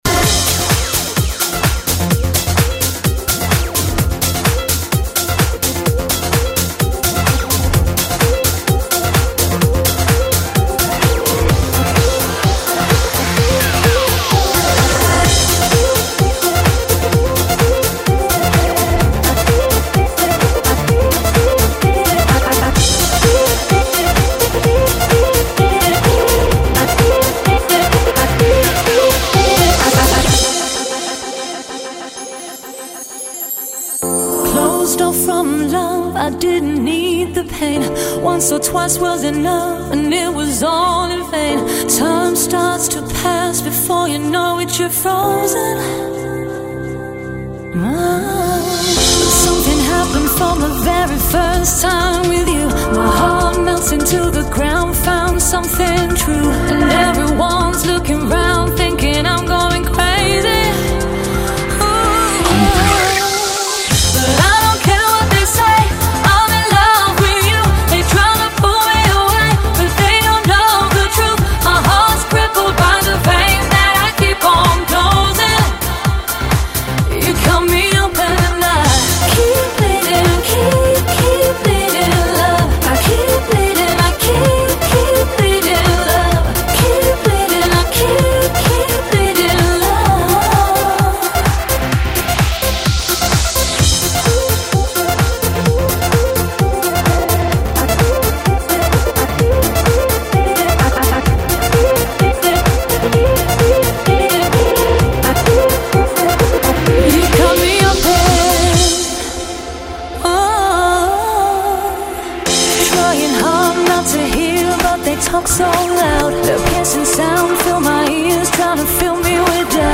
***стиль electro house*** ***